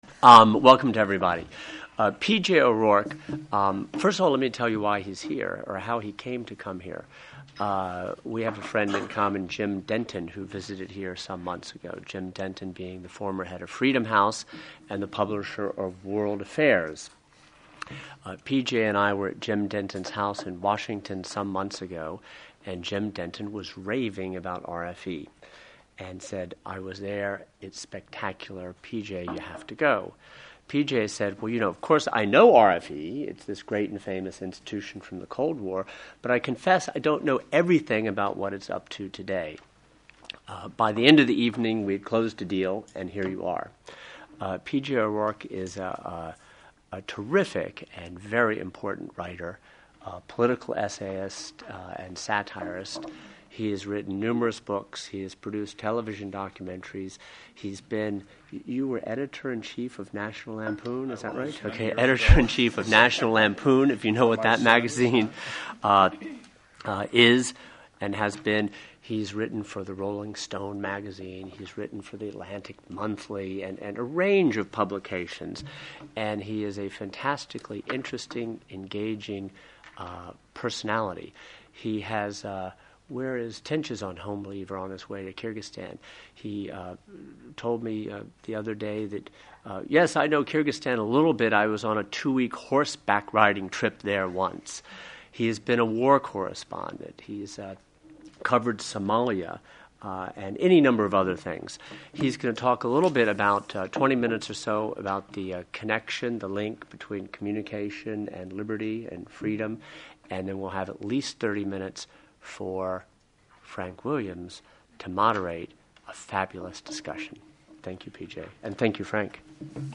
Audio of July 22, 2010 P.J. O'Rourke roundtable presentation at RFE in Prague.